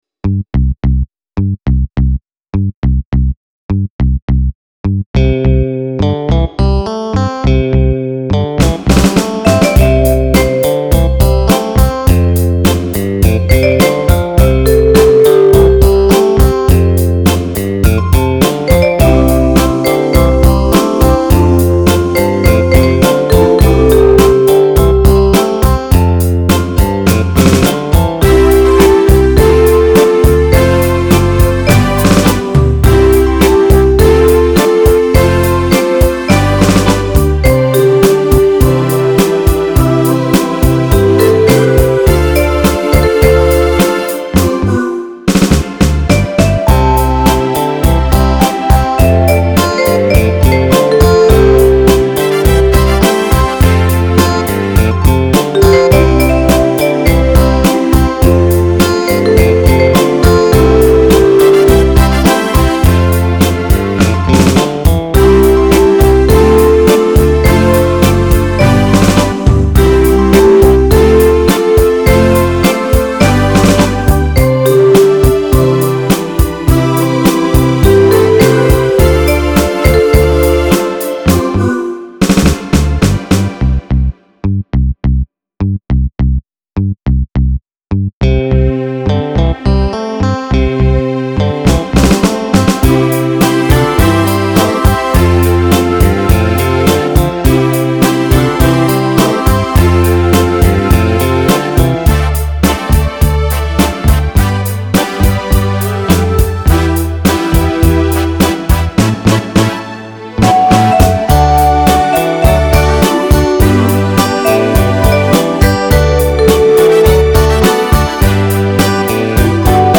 If you’re a single performer out there using backing tracks